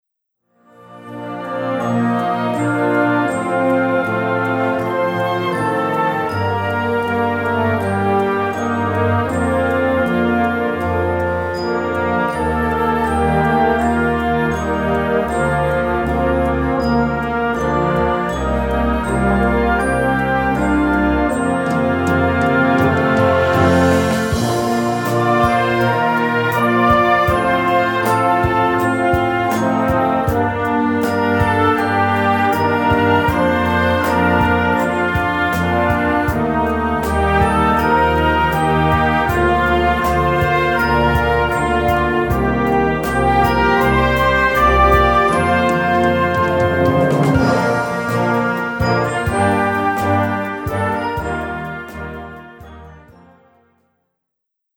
Besetzung: Blasorchester
dynamische, muntere Komposition